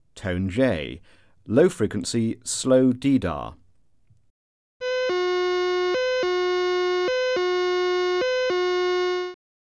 Alert Tone: J